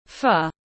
Phở tiếng anh gọi là Pho, phiên âm tiếng anh đọc là /ˈfɜː/
Pho /ˈfɜː/